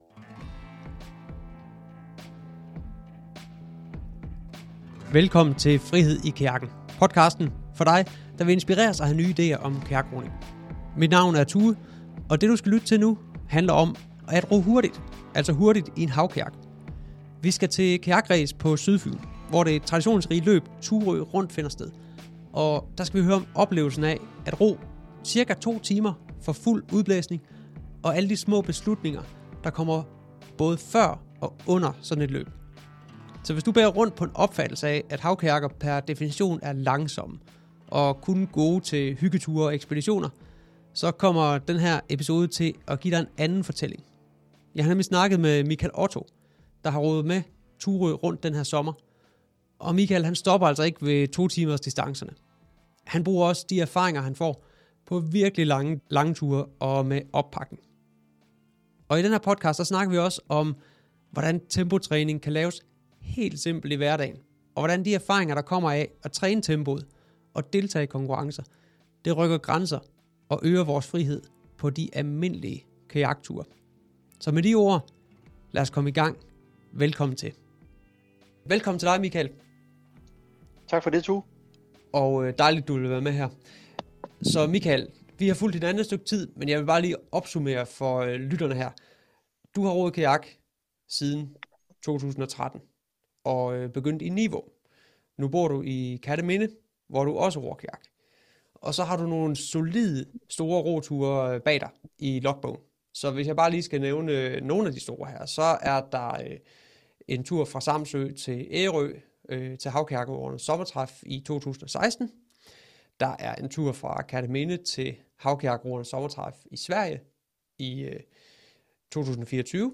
er med i studiet